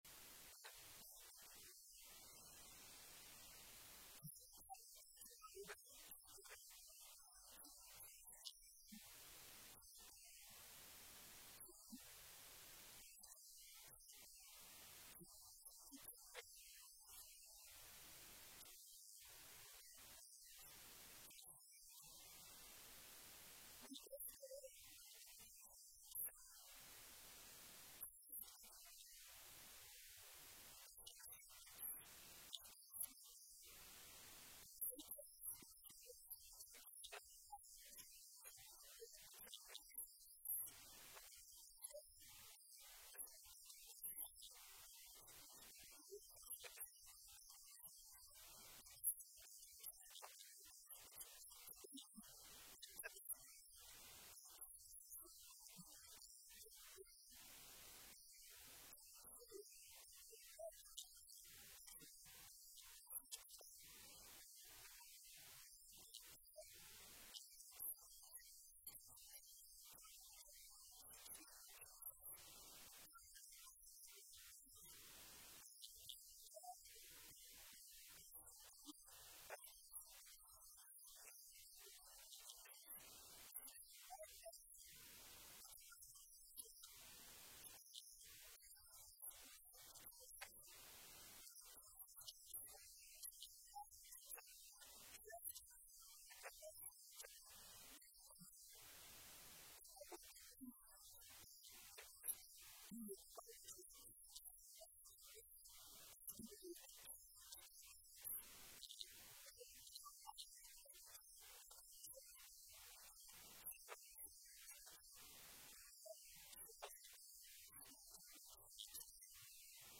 Sermon Series: 1 John